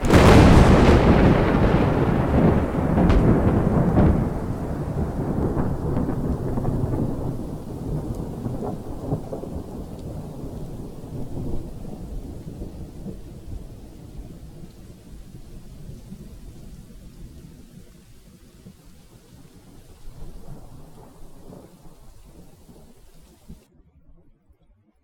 Thunder
Category 😂 Memes